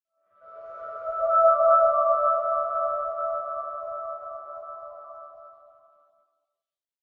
cave2.ogg